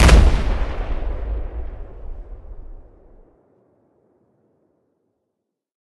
explosion_close3.ogg